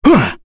Kain-Vox_Attack4.wav